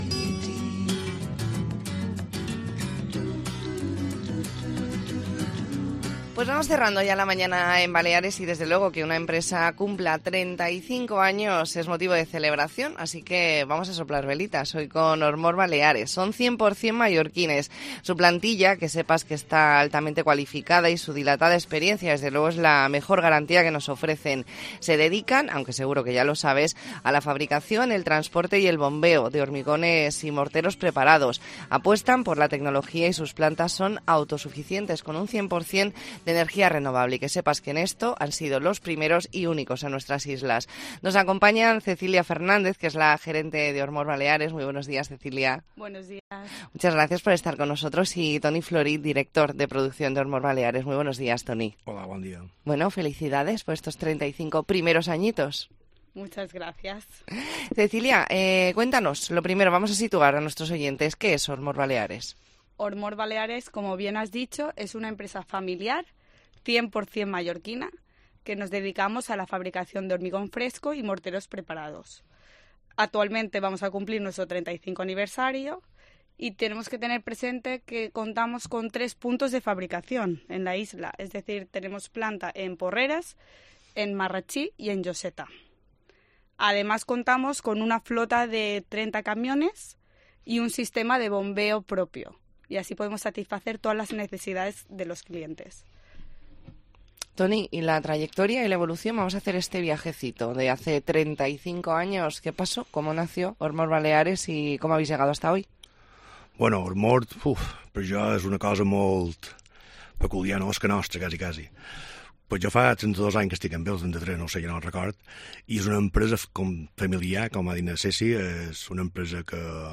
Entrevista en La Mañana en COPE Más Mallorca, miércoles 8 de noviembre de 2023.